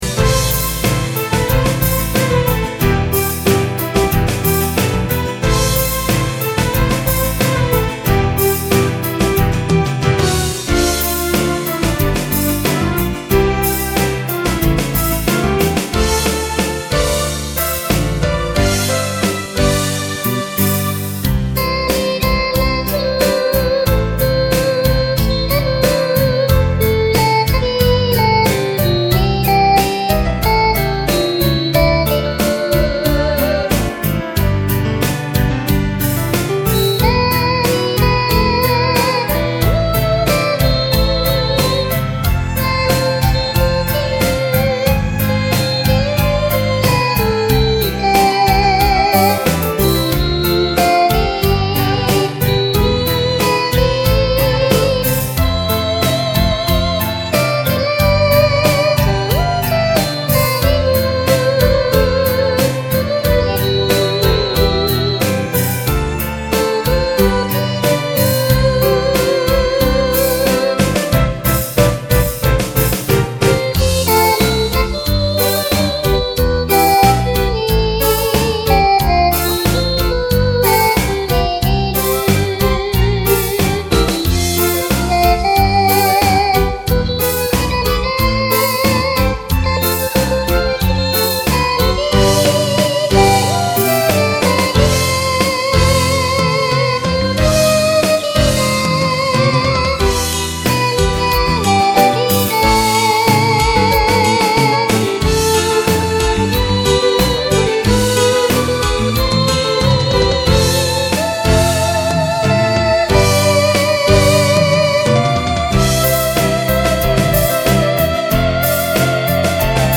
ｌｏｎｇ        ＭＰ３ファィル・・・2.89ＭＢ　　*急に音が出ます音量注意！
ｓｏｎｇ :　ｙａｍａｈａ　ＰＬＧ-100ＳＧ